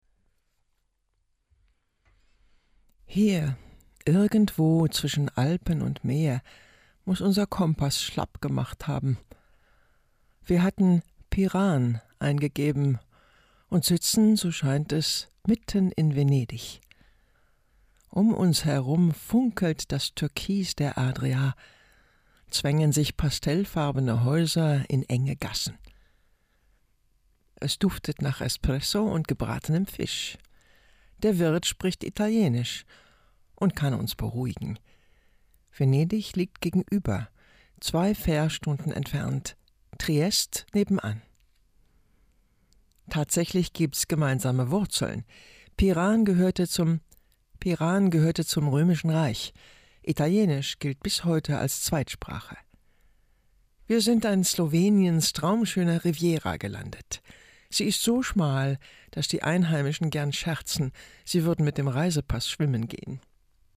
markant
Alt (50-80)